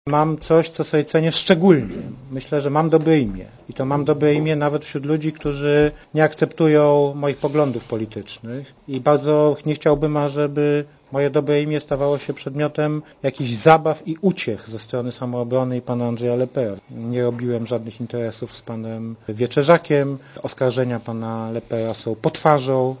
Posłuchaj komentarza Jana Rokity